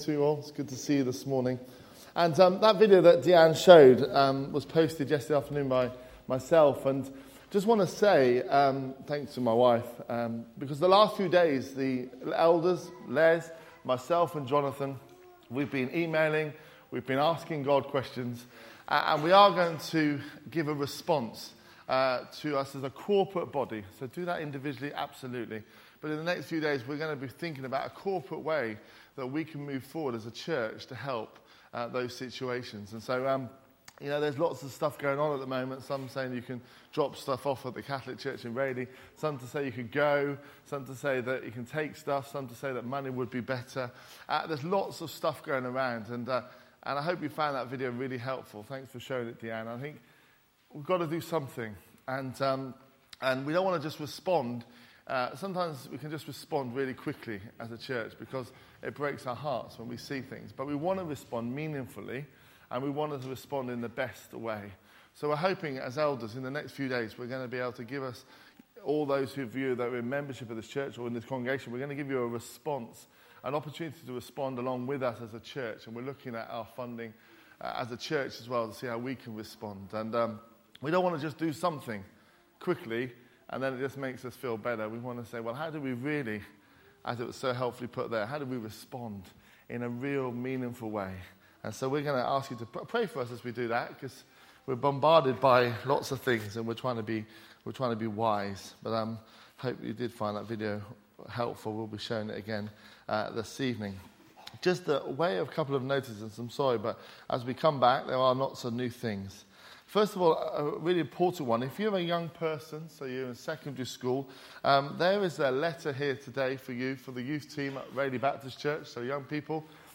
A message from the series "First Sunday."